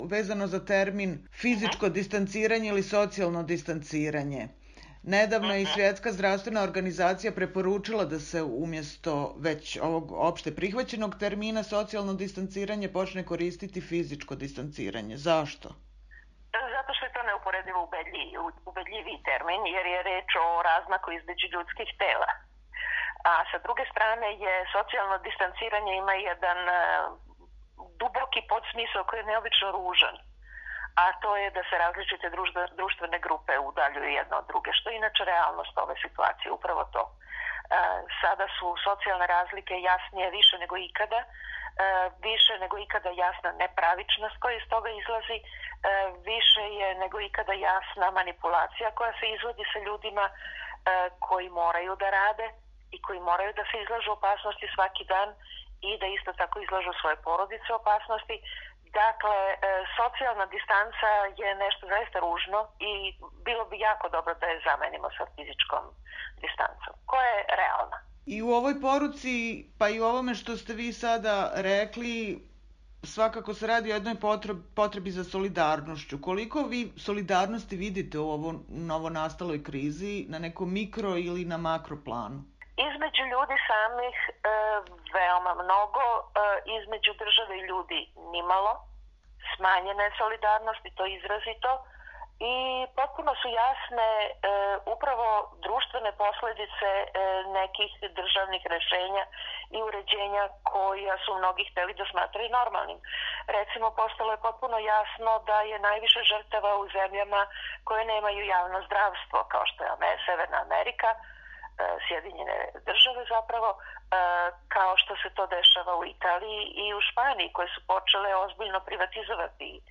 Intervju: Svetlana Slapšak